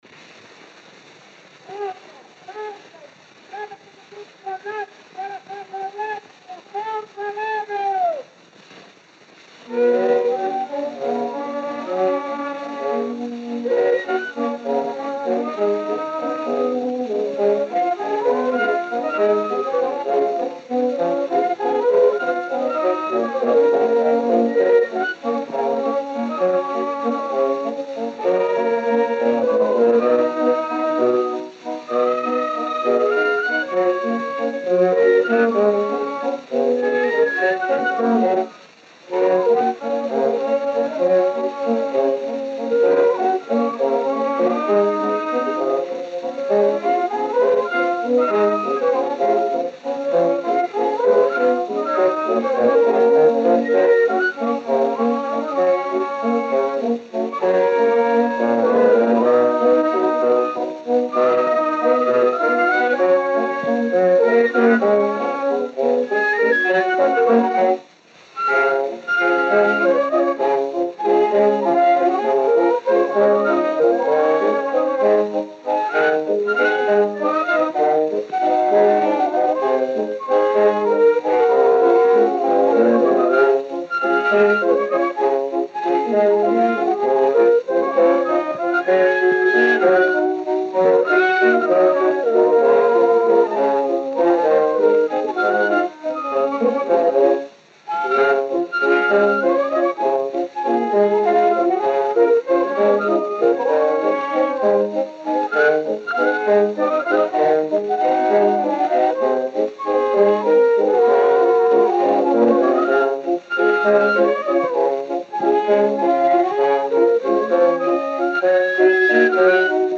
O gênero musical foi descrito como "valsa".